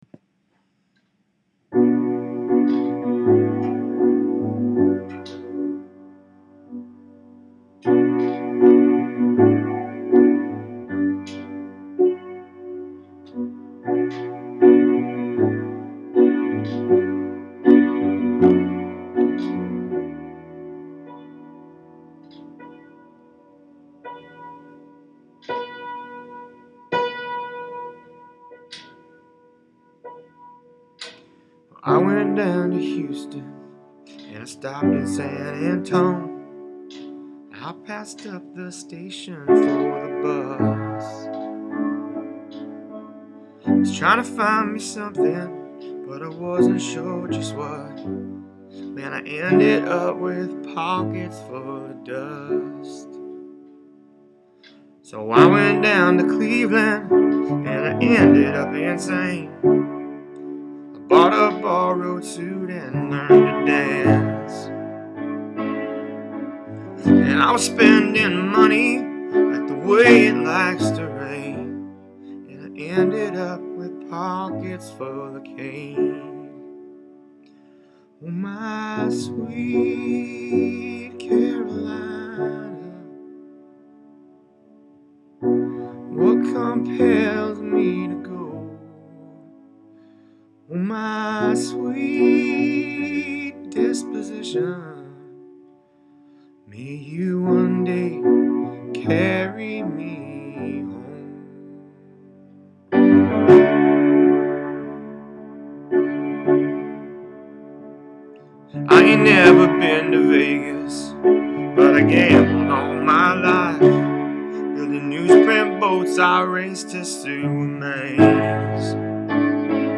The review proper: … and does a free album of covers.
utterly downbeat
piano